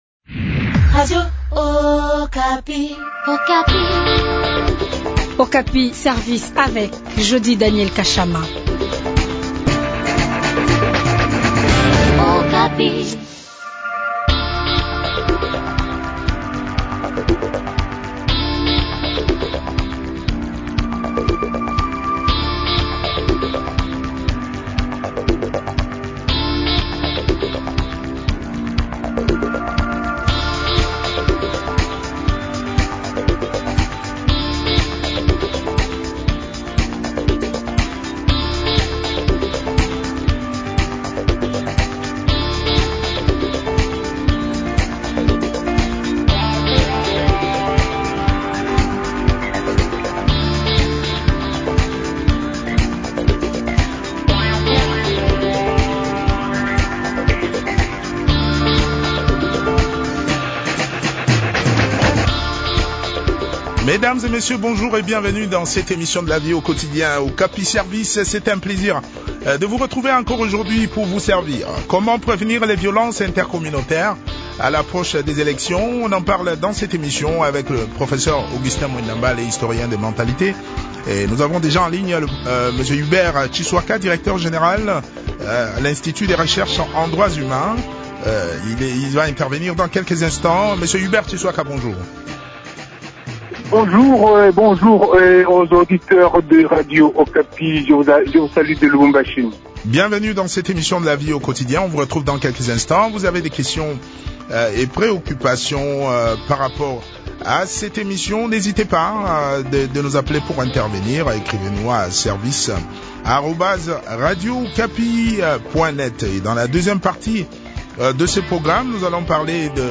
a également participé à cette interview.